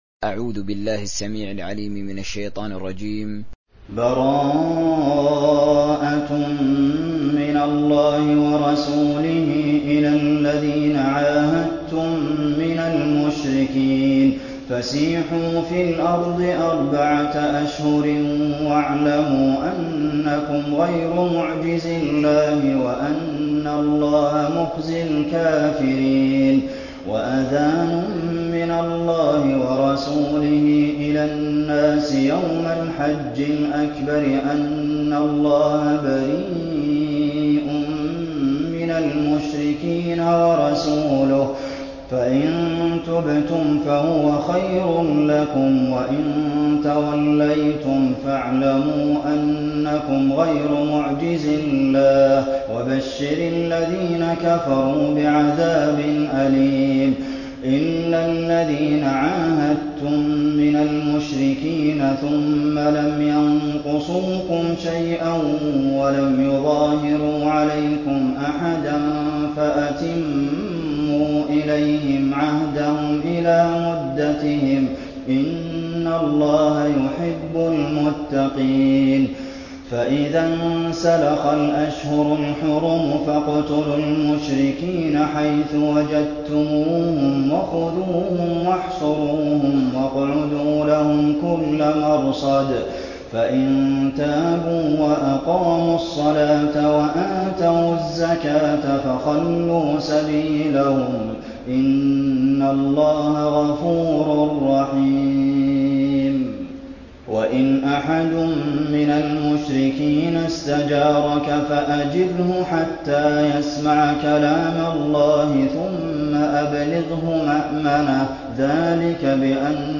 دانلود سوره التوبه حسين آل الشيخ تراويح